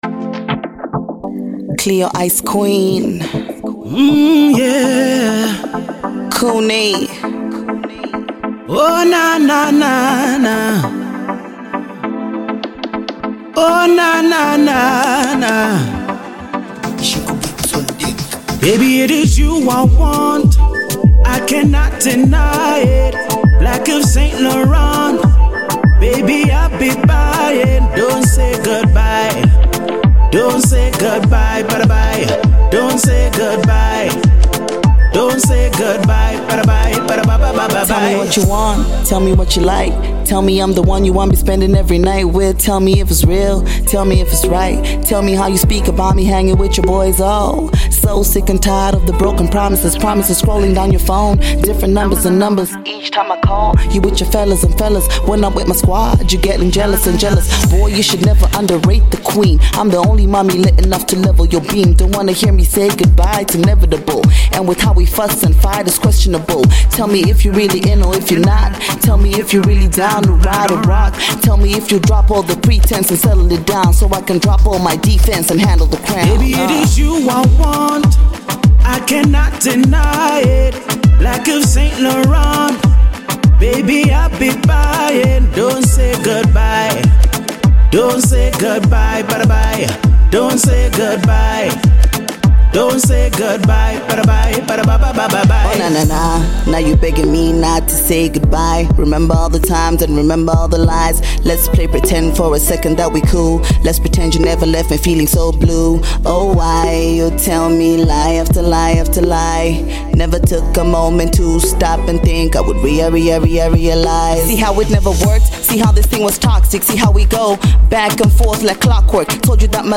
Alternative Pop